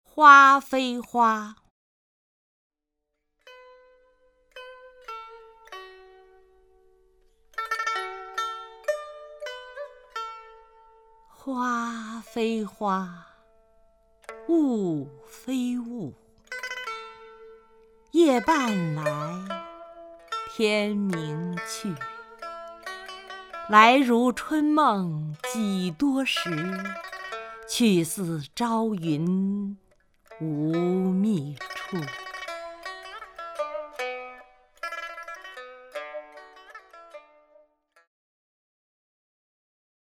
曹雷朗诵：《花非花》(（唐）白居易) （唐）白居易 名家朗诵欣赏曹雷 语文PLUS
名家朗诵欣赏